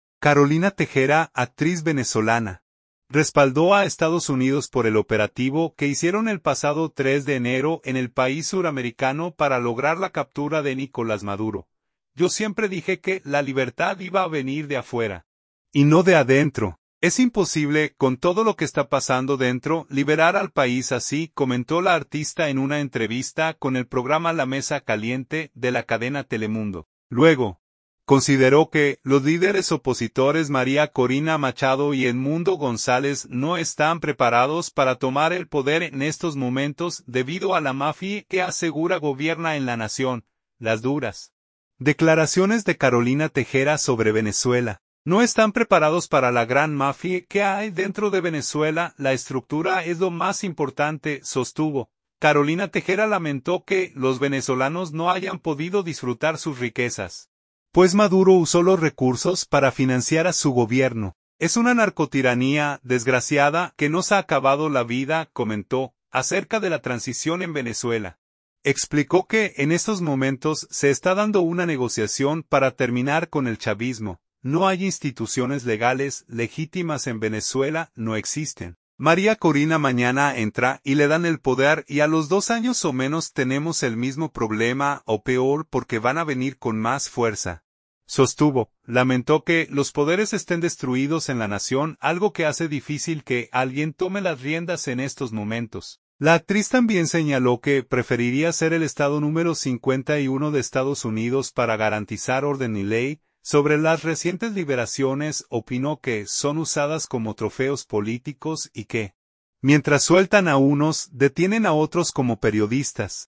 “Yo siempre dije que la libertad iba a venir de afuera y no de adentro. Es imposible, con todo lo que está pasando dentro, liberar al país así”, comentó la artista en una entrevista con el programa La Mesa Caliente, de la cadena Telemundo.